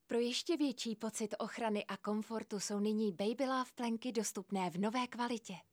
Hlasová ukázka